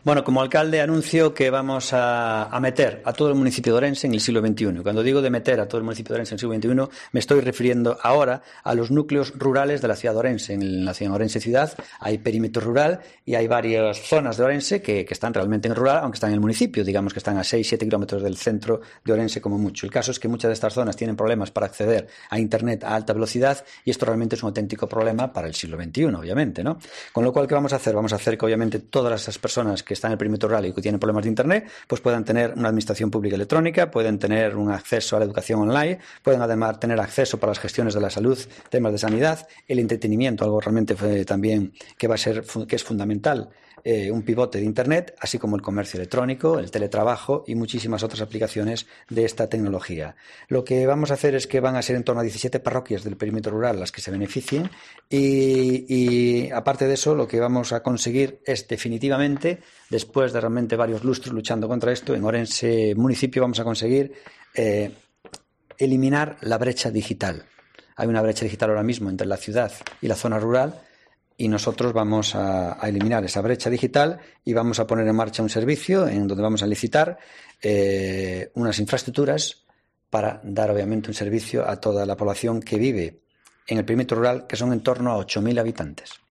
Declaraciones de Gonzalo Pérez Jácome sobre Internet de alta velocidad en todo el municipio